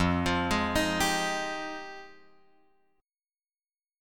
F Minor 9th